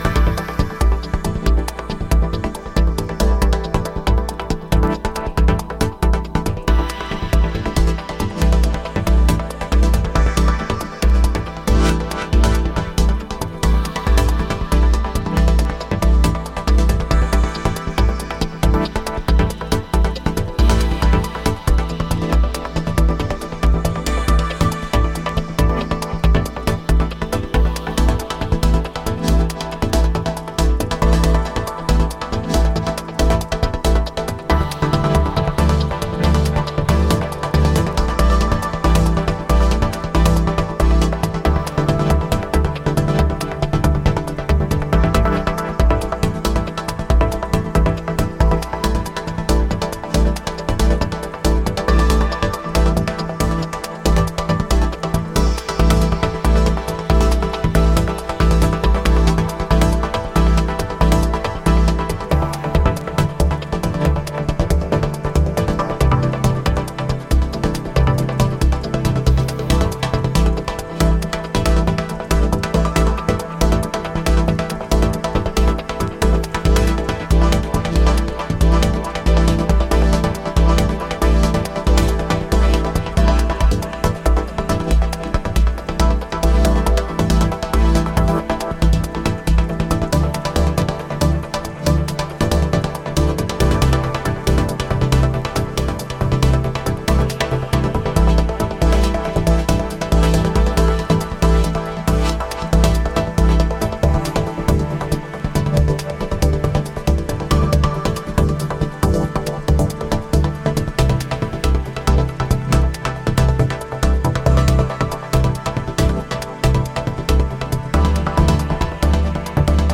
なんといっても殆どキックが入らないトランシーでエクスタティックな構成がたまらないです。